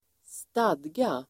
Uttal: [²st'ad:ga]